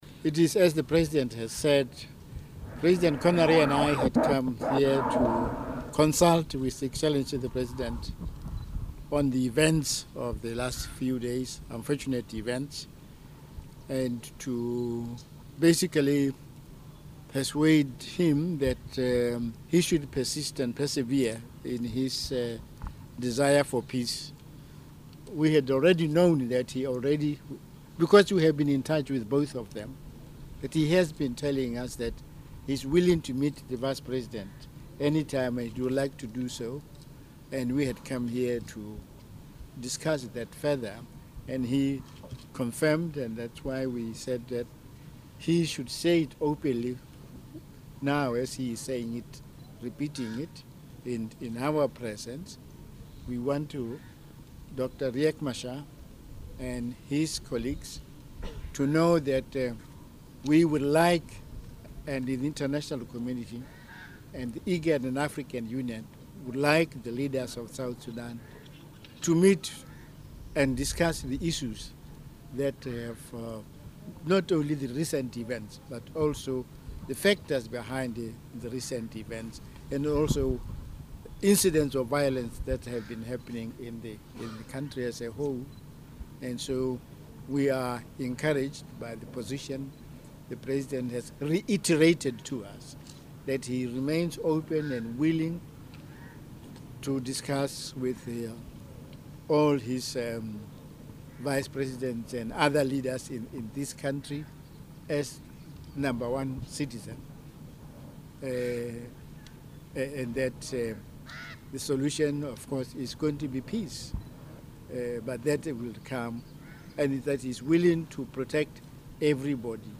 In his discussions, Mr Mogae urged all parties to observe the ceasefire and work towards restoring peace and stability. Listen to what he told Journalists in Juba